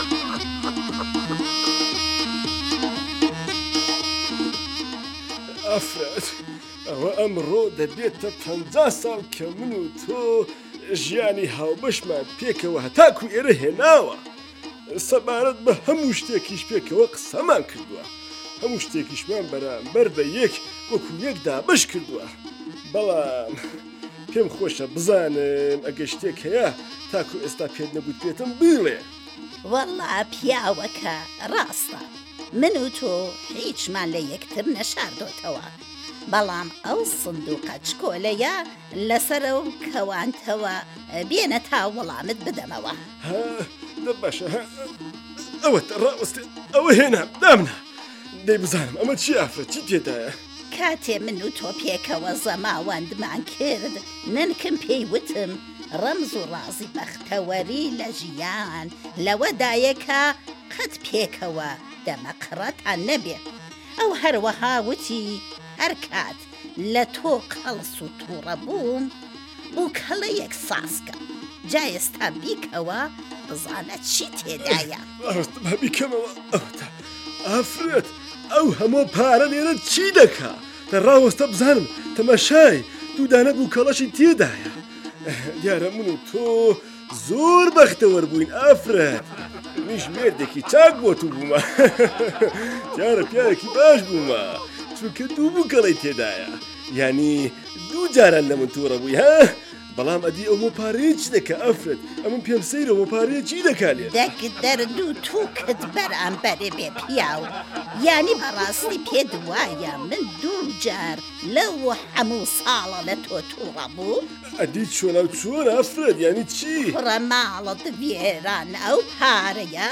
كورتەی شانۆی تەنزی ڕادیۆیی